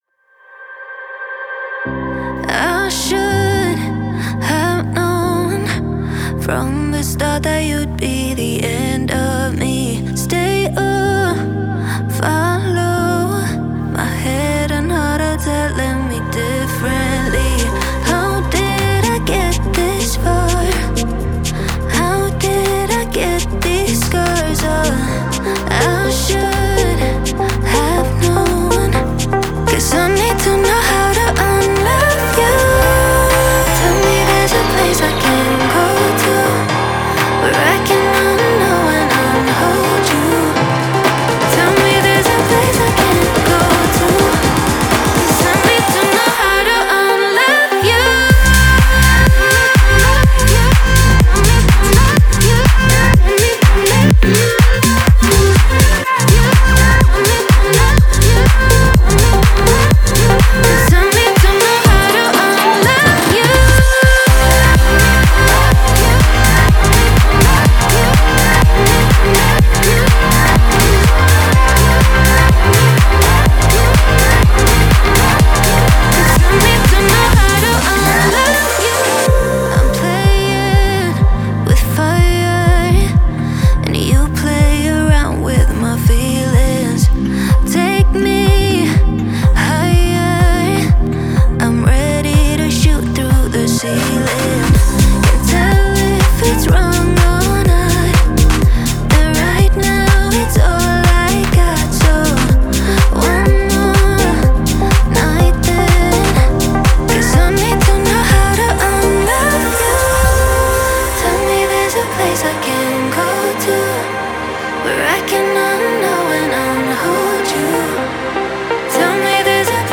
это завораживающая трек в жанре EDM